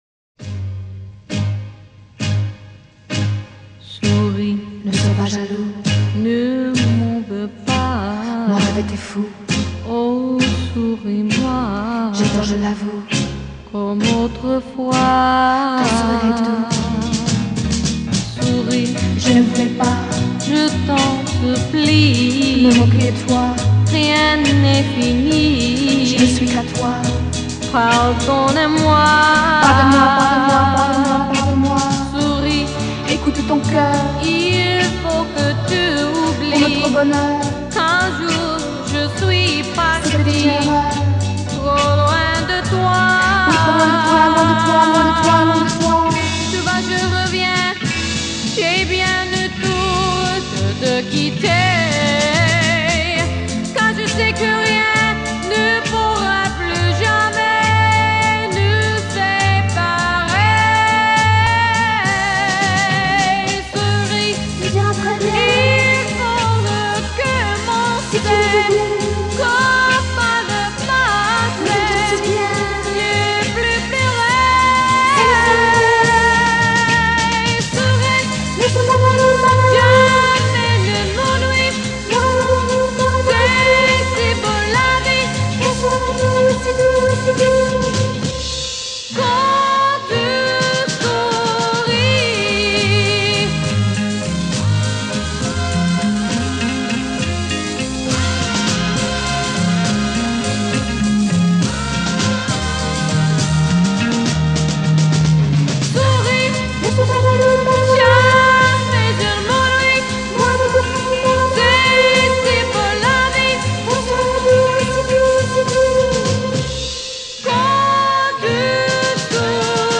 Здесь качество похуже,но и песня старенькая.